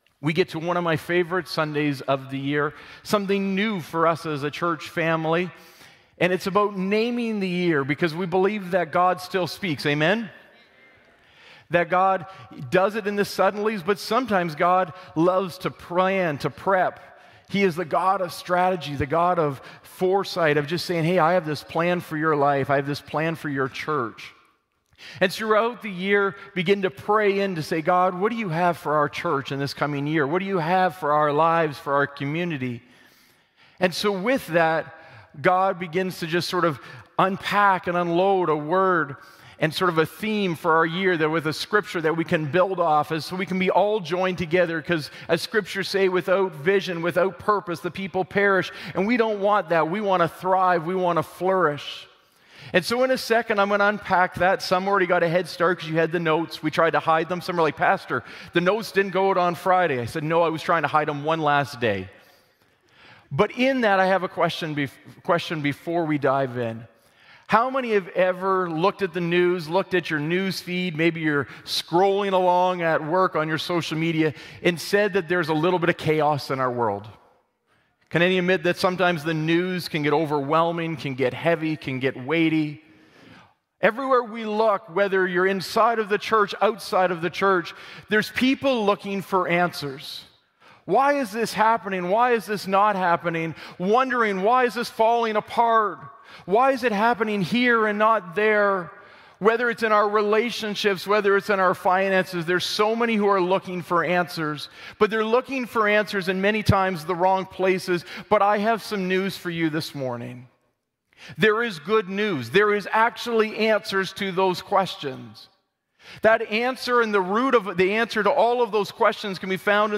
Sermon Podcast